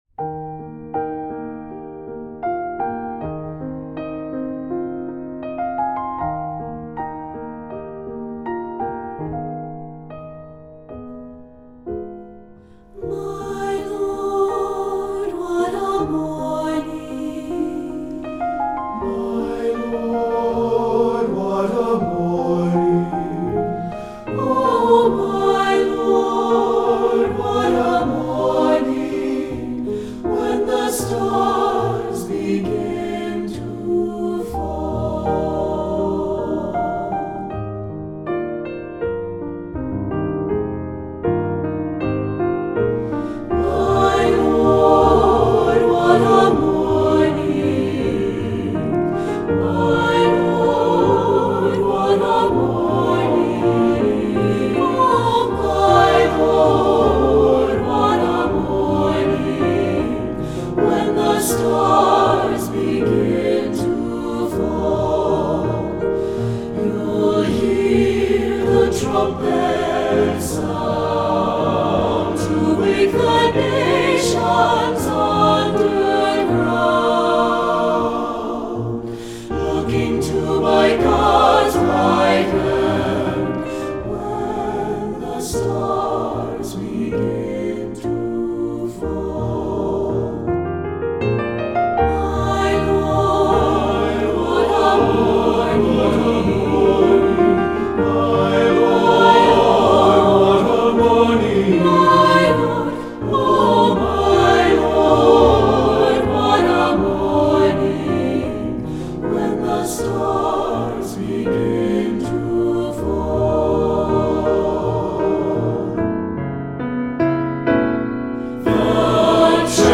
Choral Spiritual
Traditional Spiritual
SATB